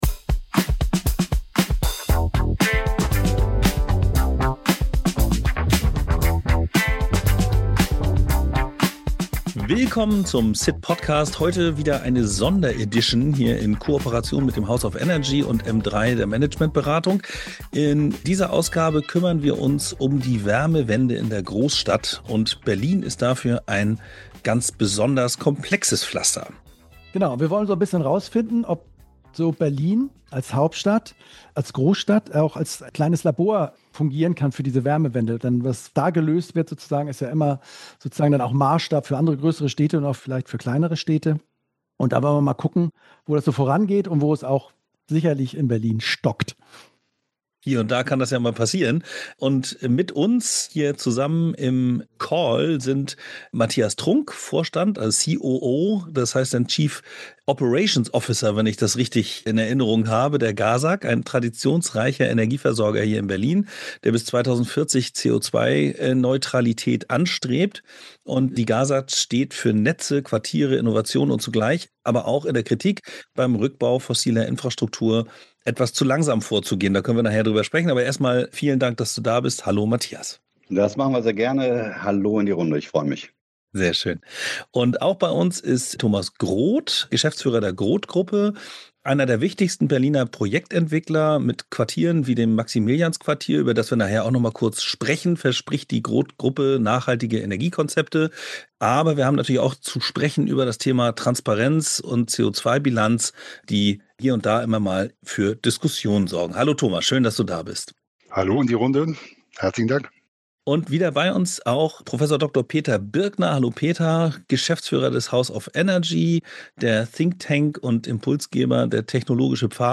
Ein ehrliches Gespräch ohne PR-Sprech – dafür mit Haltung, Klarheit und einem Hauch Humor.